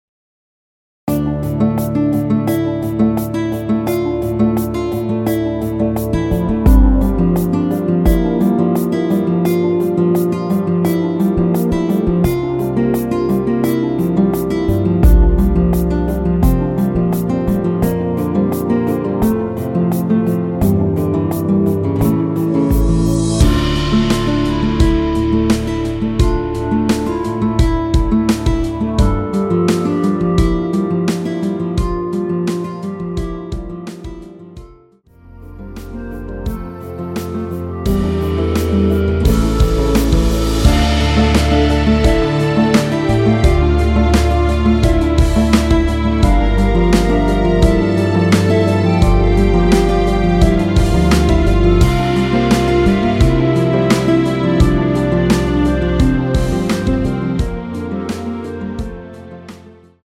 멜로디(-1) MR입니다.
원키에서(-1)내린 멜로디 포함된 MR입니다.
Dm
앞부분30초, 뒷부분30초씩 편집해서 올려 드리고 있습니다.